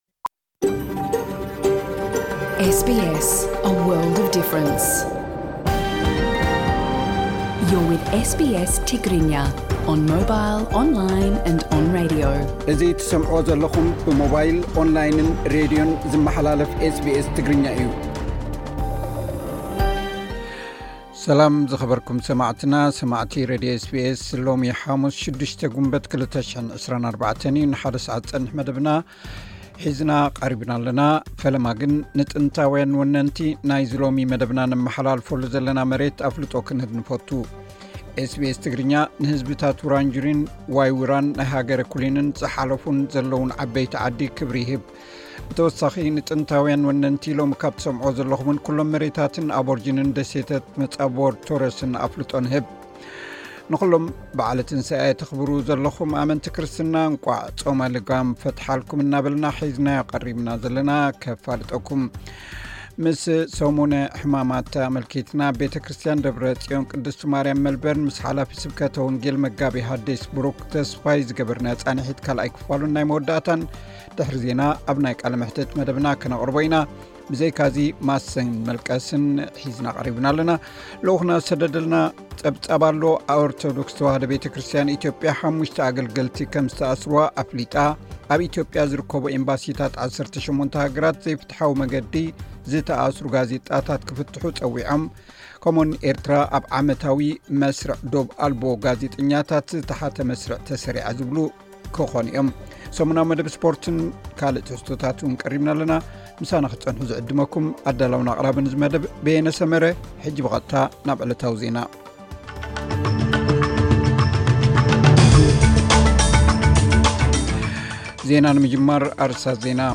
ዜናታት ኤስ ቢ ኤስ ትግርኛ (06 ግንቦት 2024)